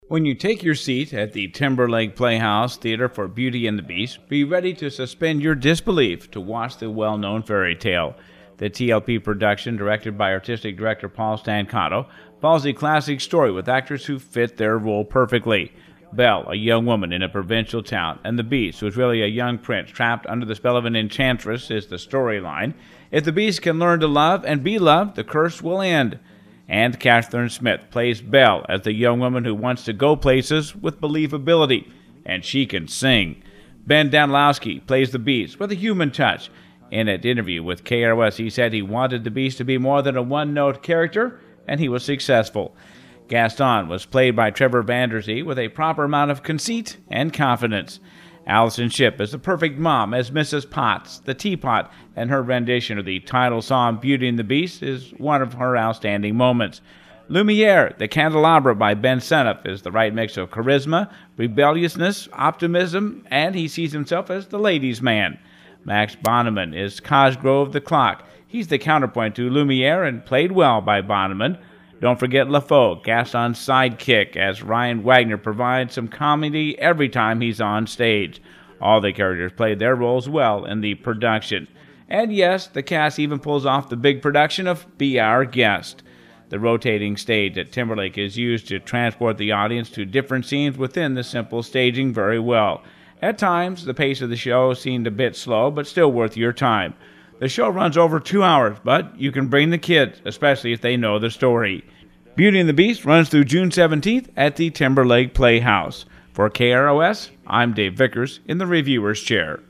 TLP-BEAUTY-AND-THE-BEAST-REVIEW.mp3